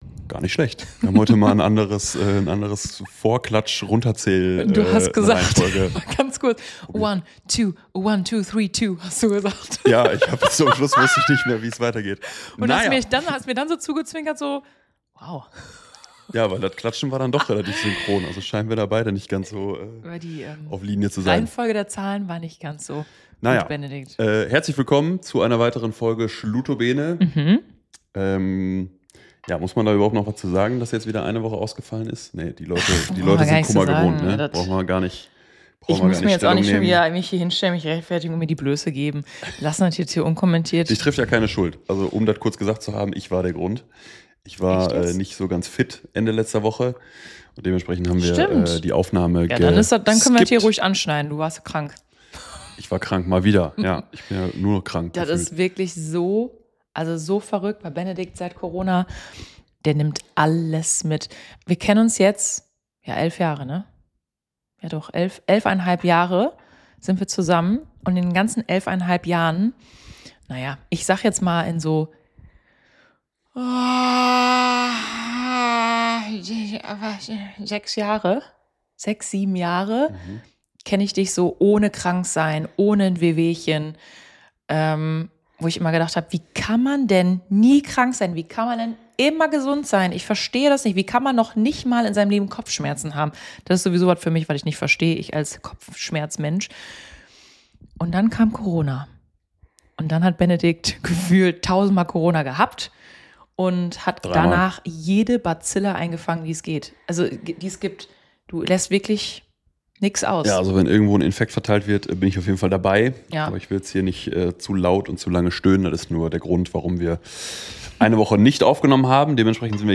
Die beiden Podcast-Hosts sitzen wieder in ihrem heimischen Wohnzimmer und bruzzeln eine neue Folge Podcast für Eure Ohren.